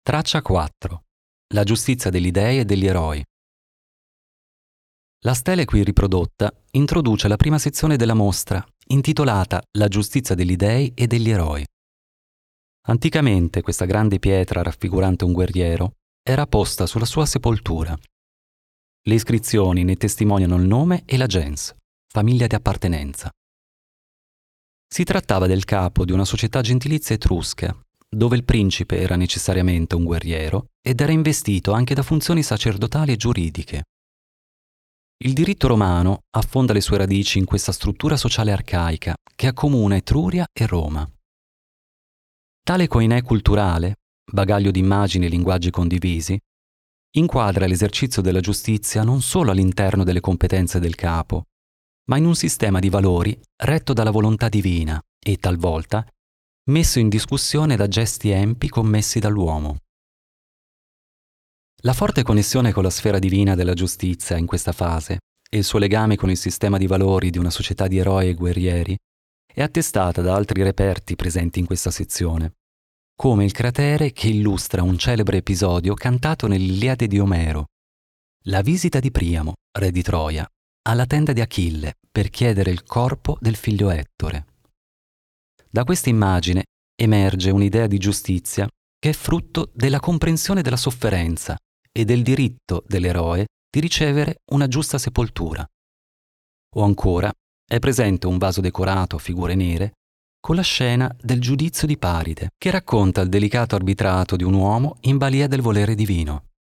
• 11 audiodescrizioni su Audiopen accompagnano il visitatore nella fruizione della mostra attraverso la lettura delle singole opere e dei relativi disegni a rilievo.